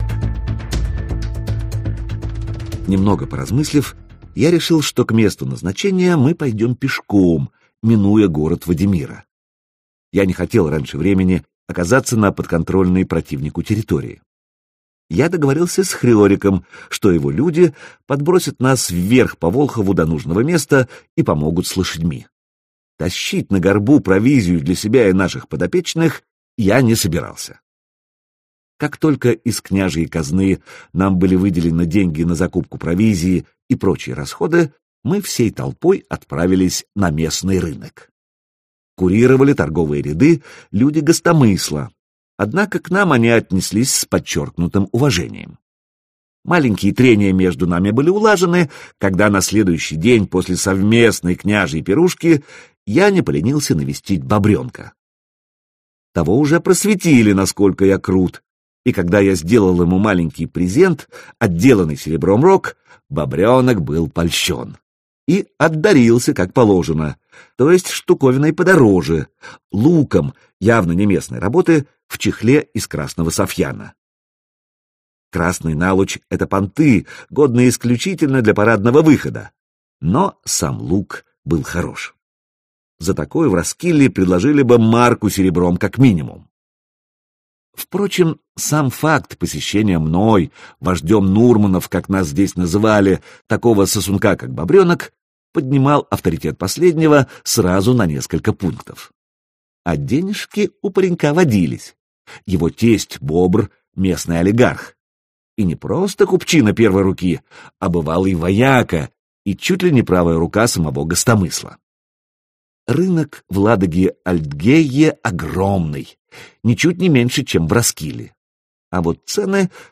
Аудиокнига Земля предков | Библиотека аудиокниг